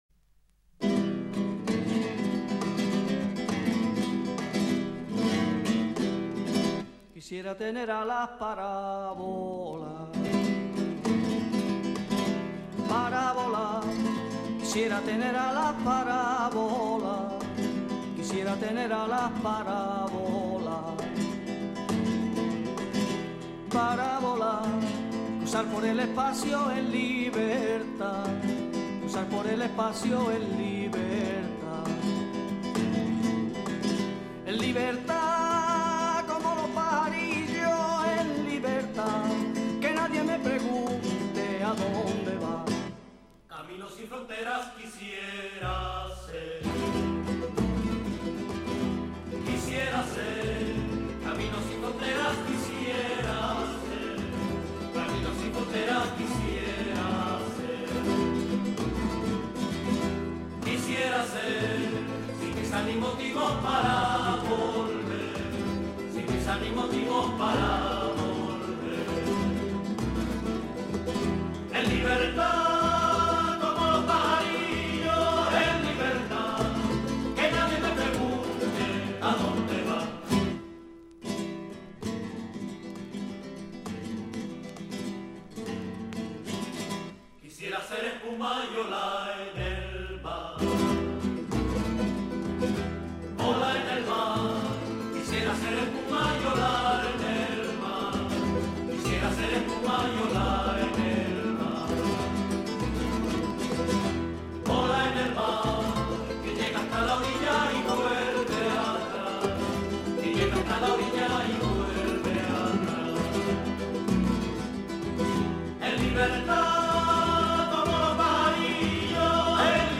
sevillanas